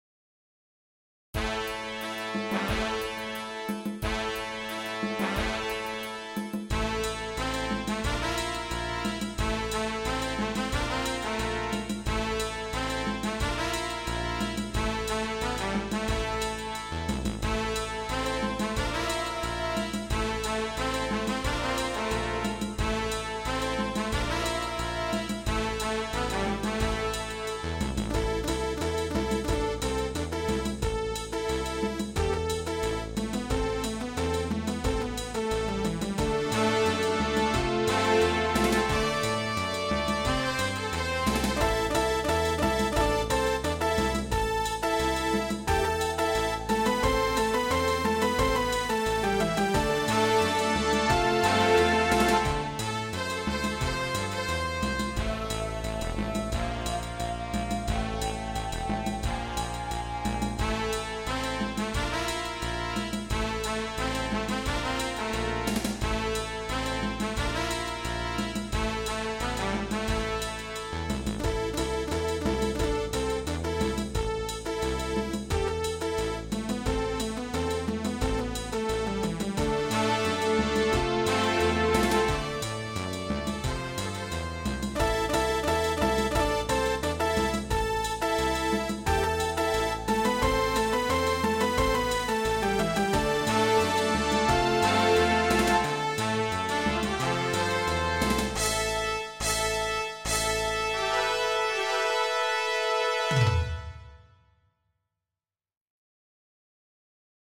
Titelmusik